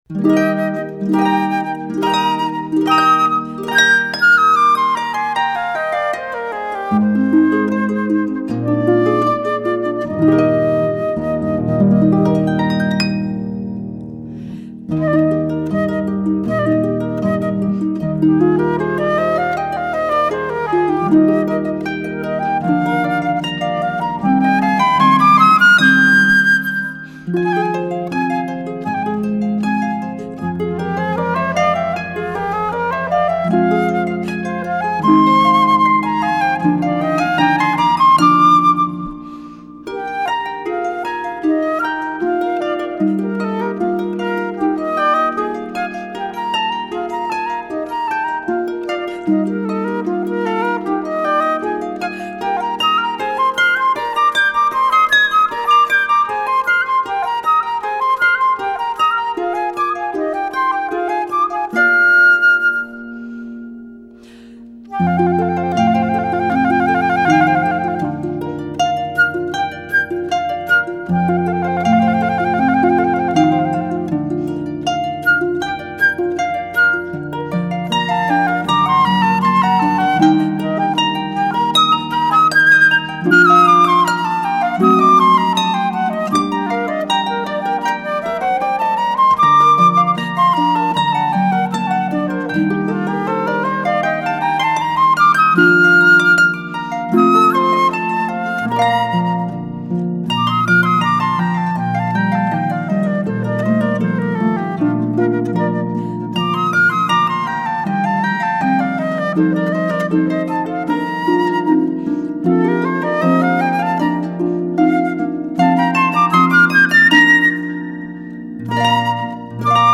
Harpist 2
harp2-9.mp3